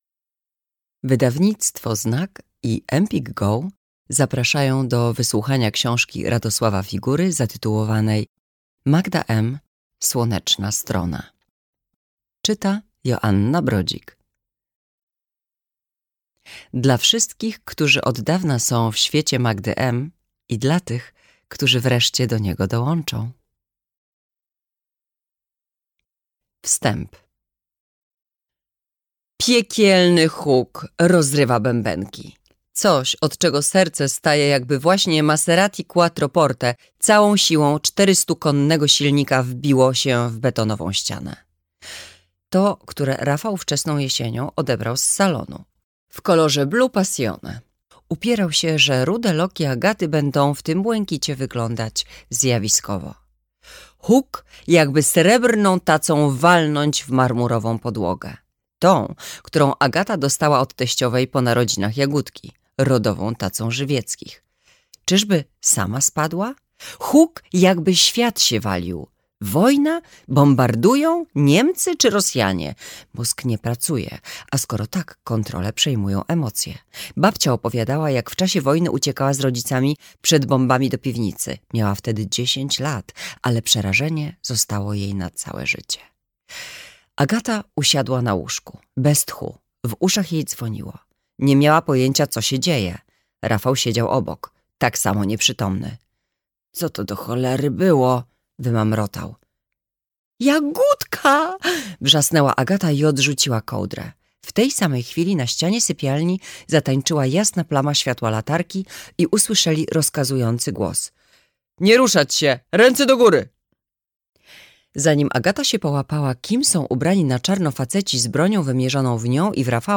Magda M. Słoneczna strona - Figura Radosław - audiobook